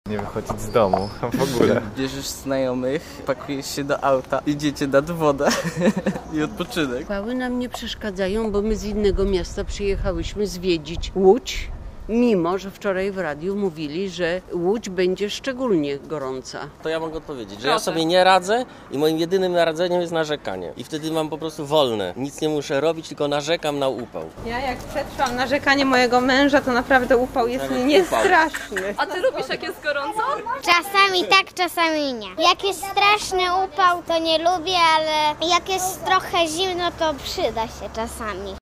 Posłuchaj relacji: Nazwa Plik Autor Jak mieszkańcy Łodzi radzą sobie z upałami? audio (m4a) audio (oga) Maksymalna temperatura w Łodzi ma dziś wynieść 33 stopnie.